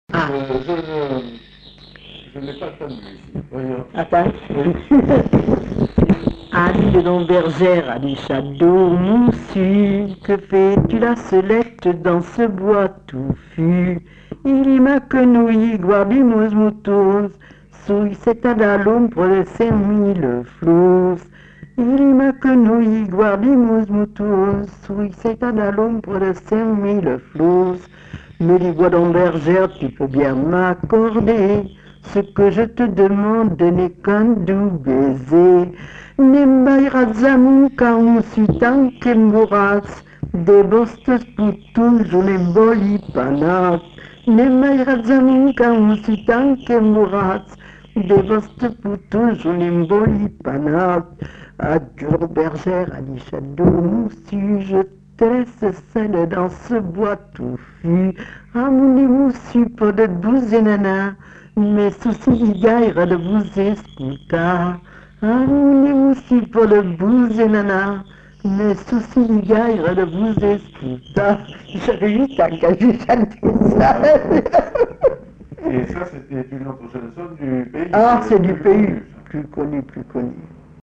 Aire culturelle : Bazadais
Lieu : Grignols
Genre : chant
Effectif : 1
Type de voix : voix de femme
Production du son : chanté